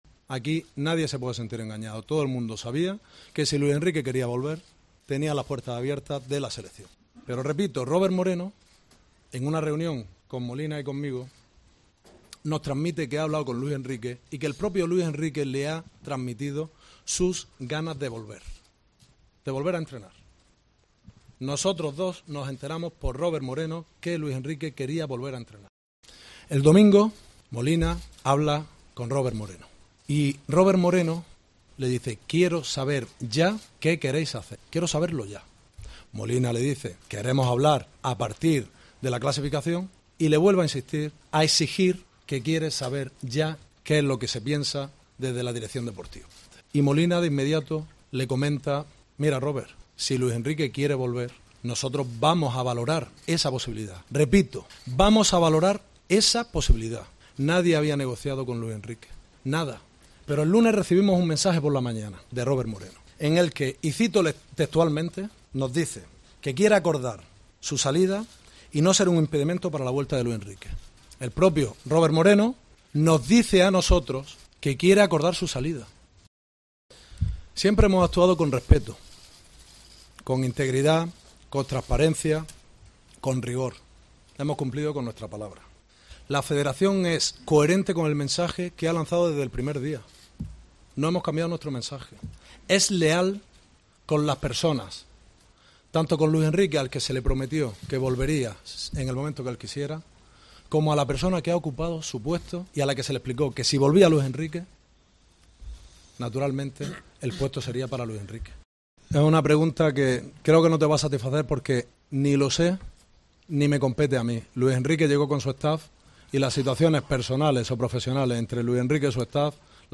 AUDIO: El president de la Federació, Luis Rubiales, explica el retorn de Luis Enrique a la selecció i la crisi amb Robert Moreno